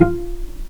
vc_pz-F4-pp.AIF